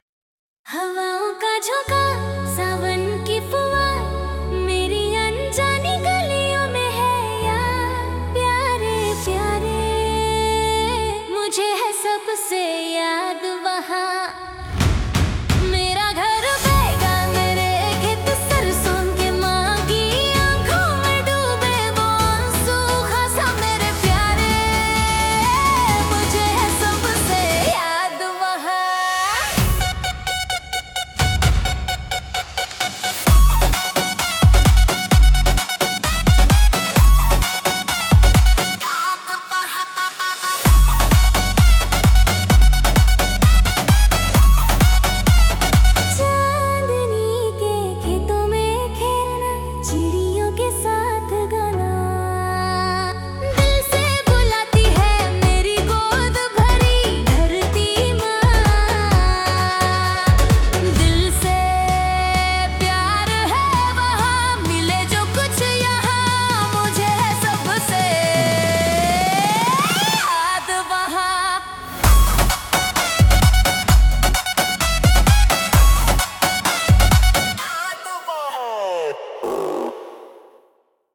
Hindi Bubblegum Dance